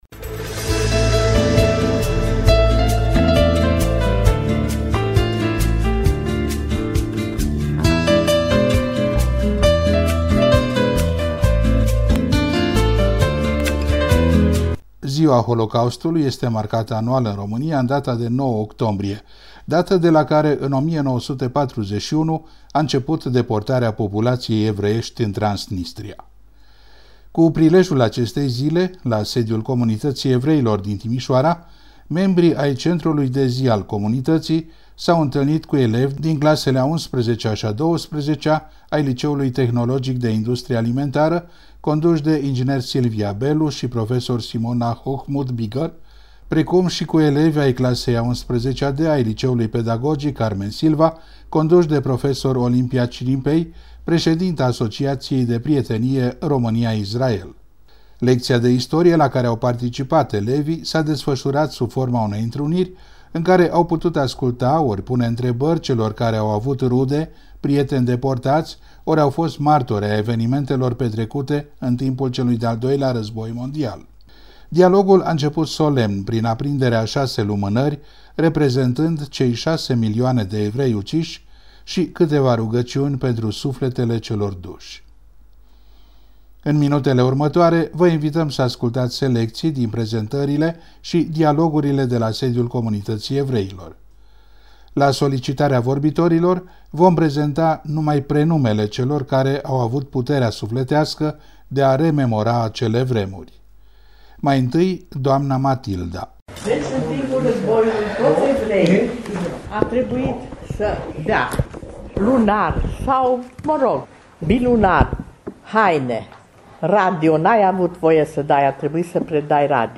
Emisiunea radio "Convietuiri ebraice"